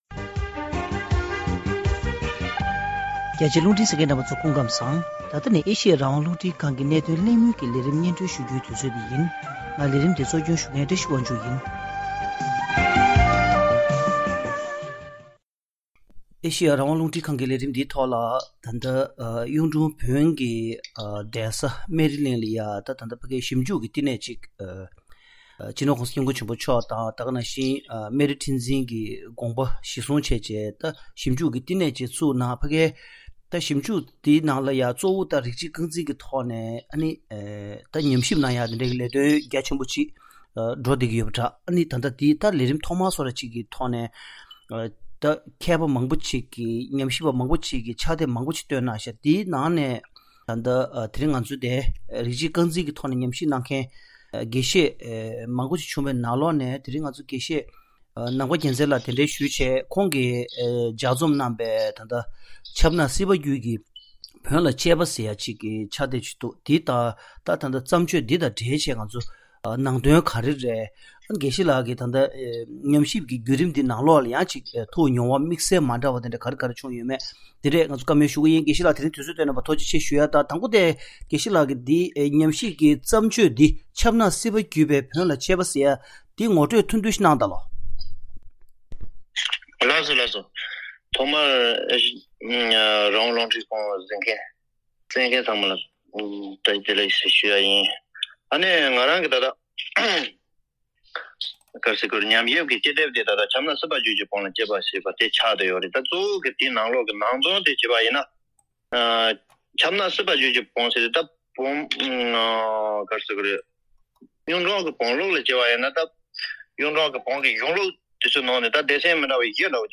དེ་རིང་གནད་དོན་གླེང་མོལ་གྱི་ལས་རིམ་ནང་།